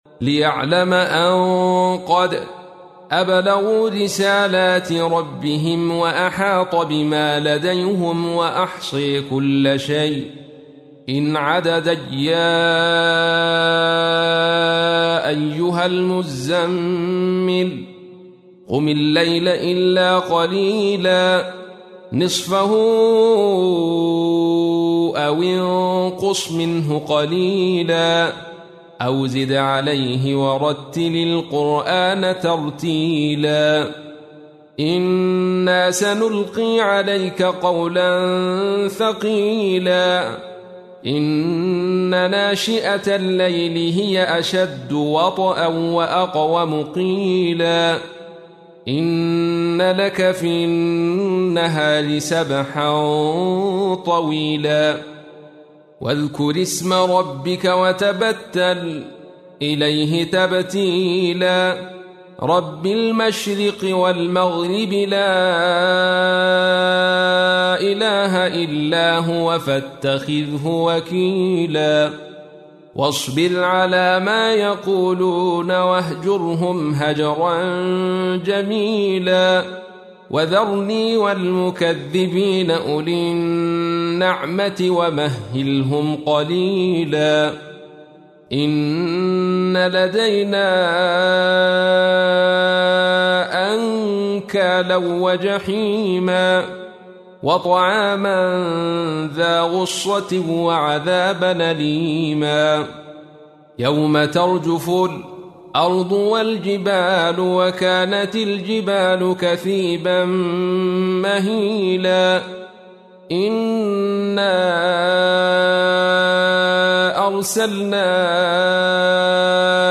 تحميل : 73. سورة المزمل / القارئ عبد الرشيد صوفي / القرآن الكريم / موقع يا حسين